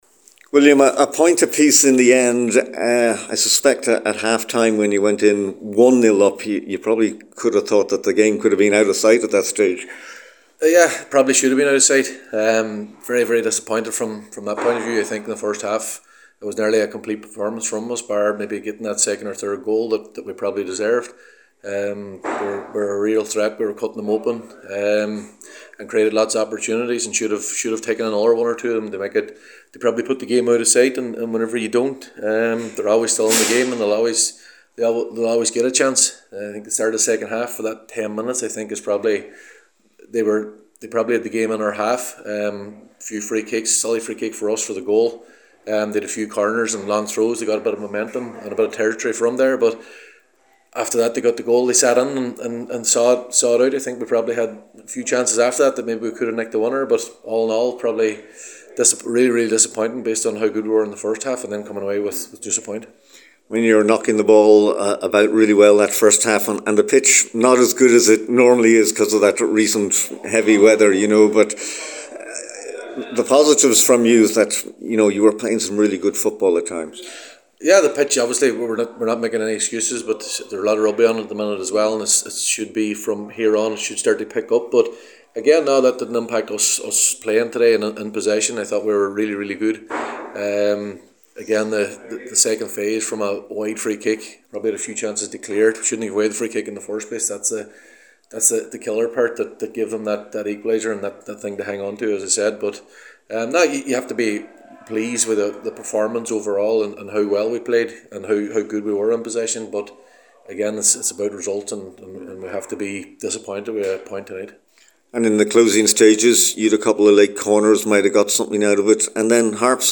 at full time at the Belfield Bowl…